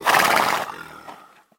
SFX_caballoBufido2.wav